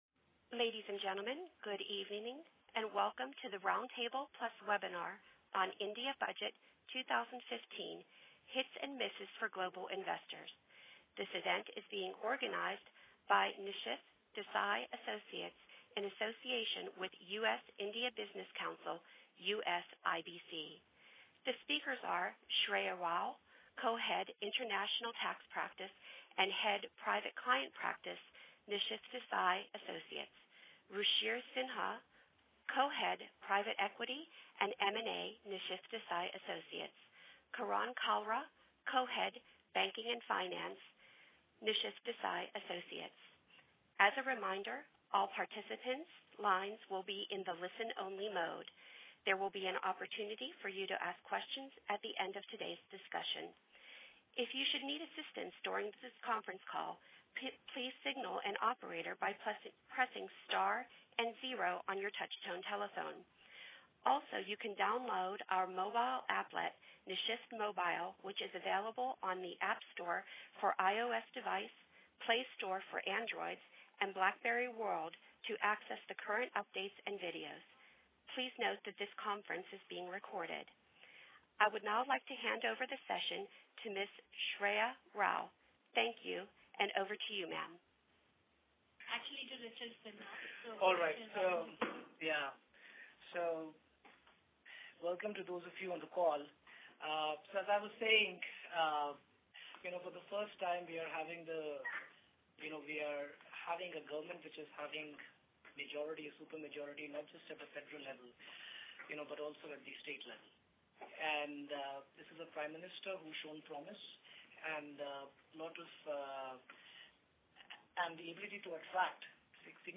Roundtable in NYC: India Budget 2015 – Hits & Misses for Global Investors (Wednesday, March 11, 2015)